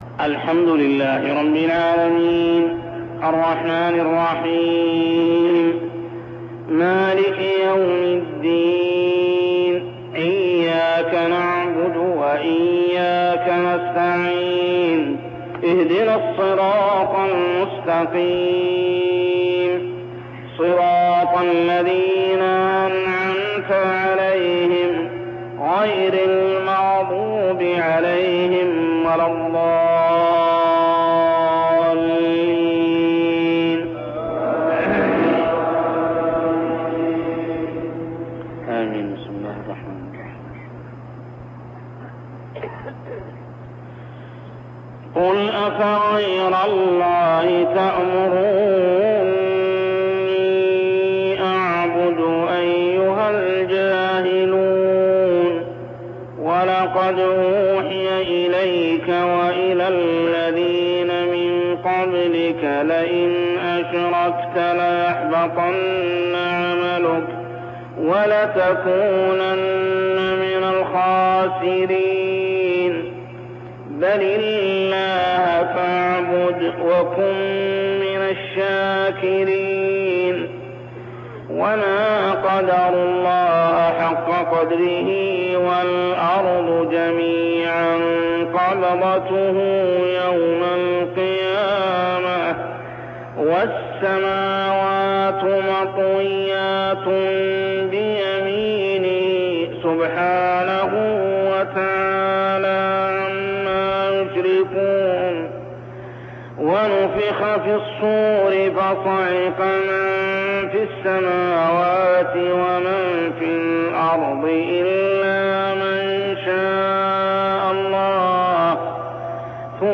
تلاوة من صلاة الفجر لخواتيم سورة الزمر 64-75 عام 1402هـ | Fajr prayer Surah Az-Zumar > 1402 🕋 > الفروض - تلاوات الحرمين